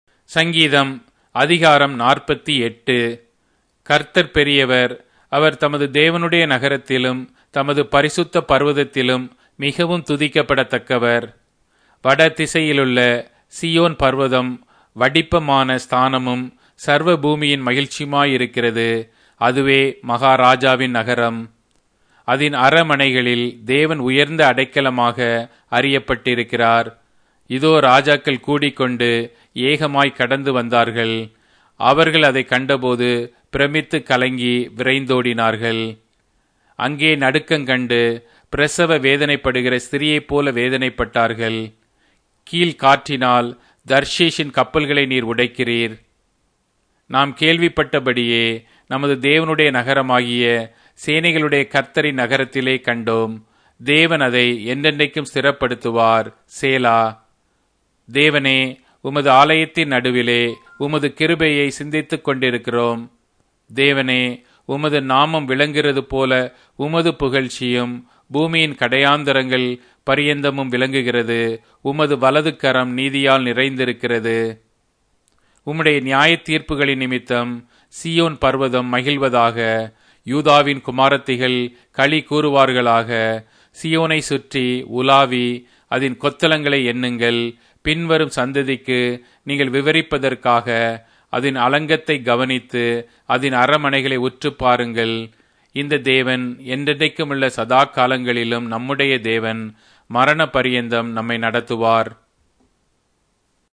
Tamil Audio Bible - Psalms 138 in Urv bible version